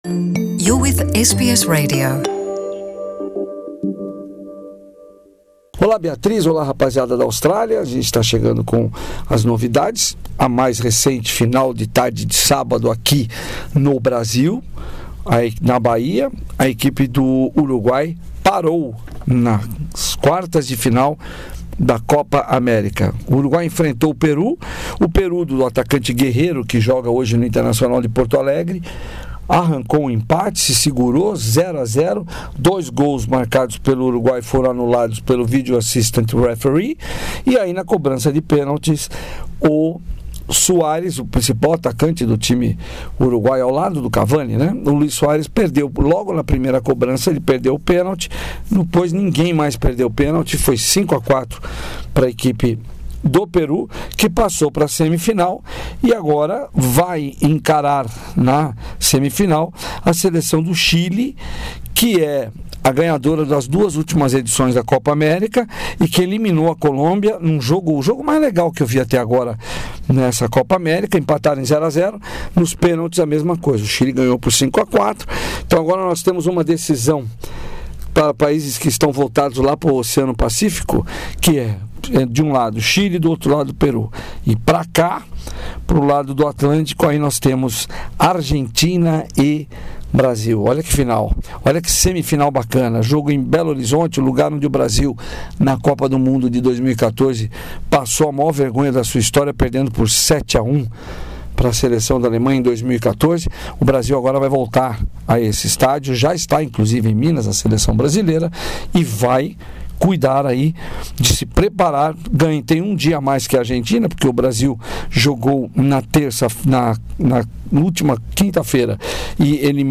reportagem
direto de São Paulo.